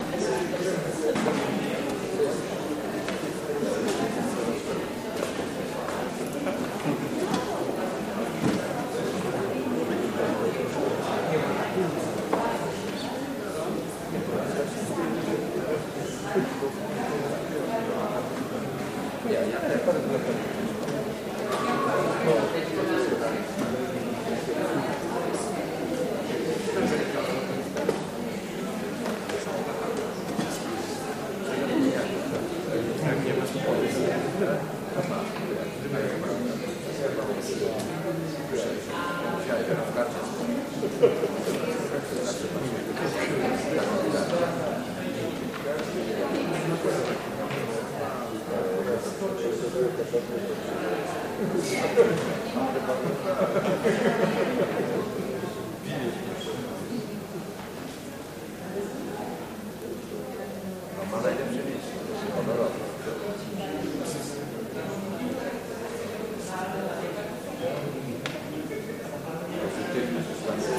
Lounge, Lobby Walla Medium Walla